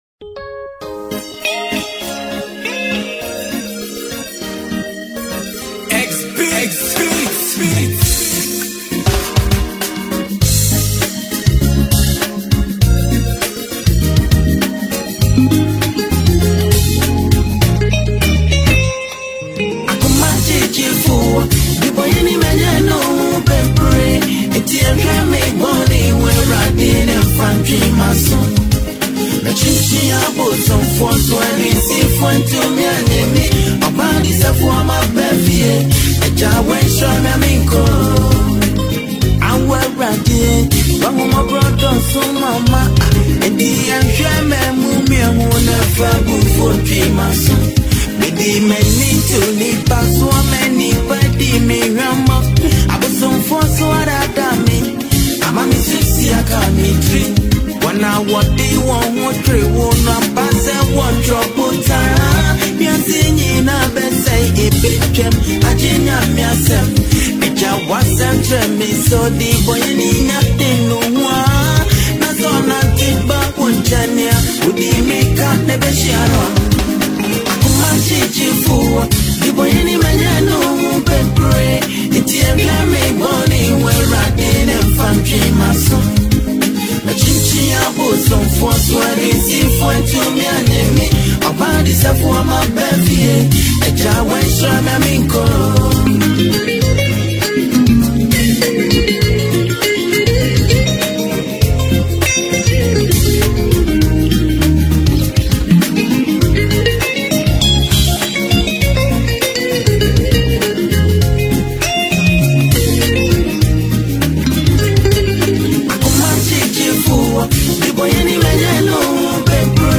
emotional highlife mp3
With his soothing voice and rich lyrical delivery